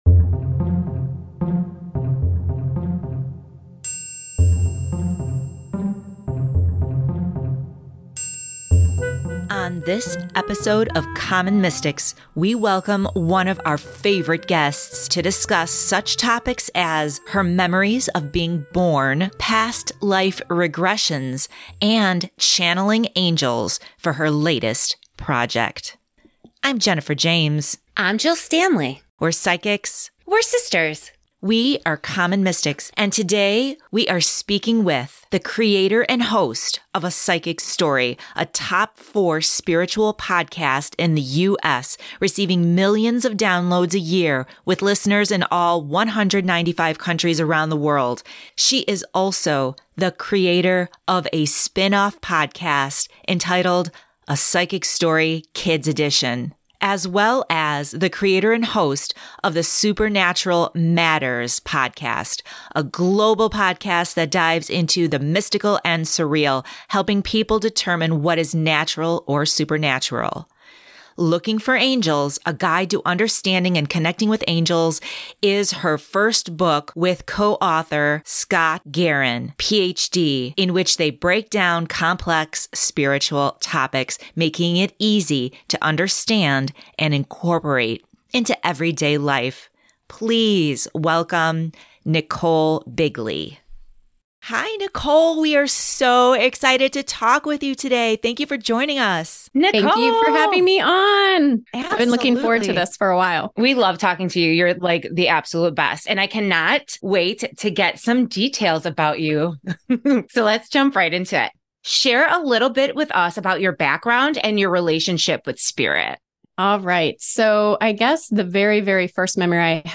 Common Mystics 78: Interview